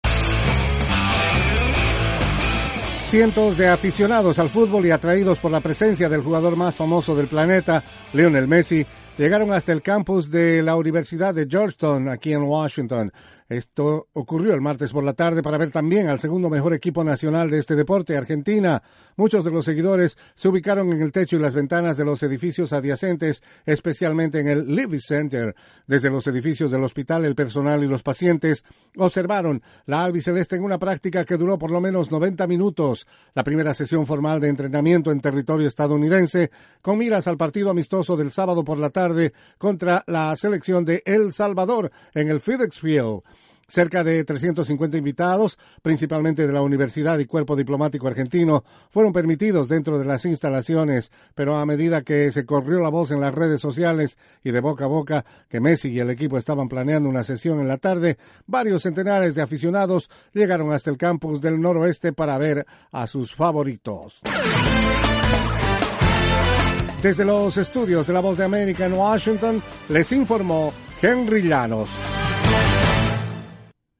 La selección de fútbol de Argentina se encuentra en territorio estadounidense entrenando para encarar su compromiso amistoso frente al seleccionado de El Salvador el día sábado. Informa